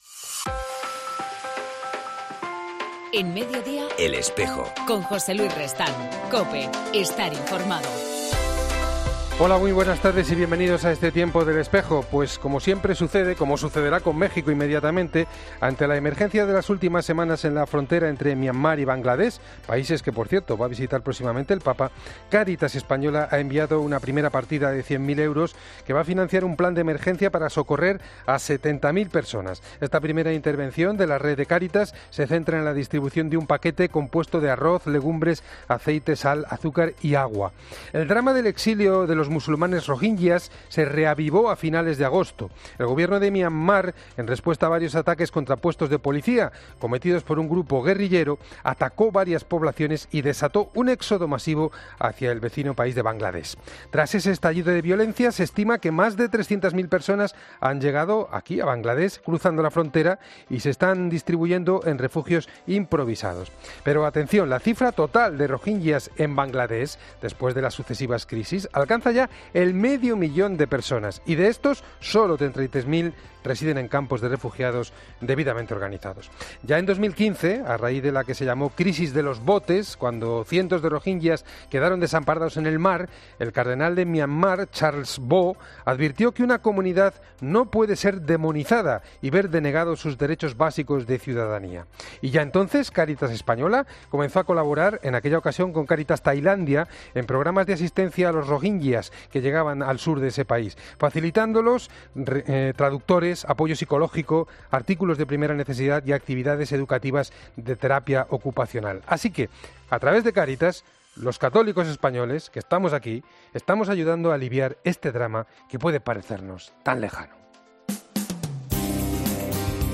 En El Espejo del 20 de septiembre hablamos con el obispo de Orense, Leonardo Lemos